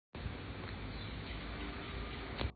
內湖區內湖路二段103巷住宅外
均能音量: 48.4 dBA 最大音量: 66.2 dBA 地點類型: 住宅外 寧靜程度: 5分 (1分 – 非常不寧靜，5分 – 非常寧靜)
說明描述: 沒有噪音，人的心情寧靜平和 聲音類型: 動物、野生動物、戶外、鄉村或自然